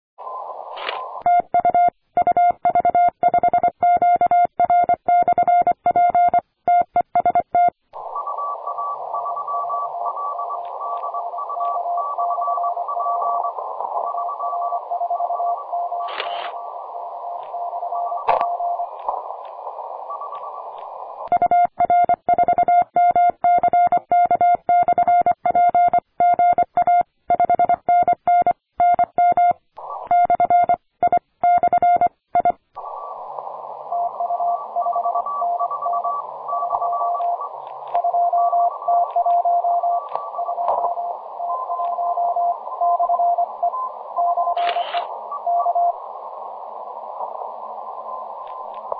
Еще и прилично слышно - у меня на 40м ваще символическая антенна высотой 4м среди деревьев была, но у нас и позиции в прямой видимости фактически - и Азов и Черное море на горизонте как на ладони.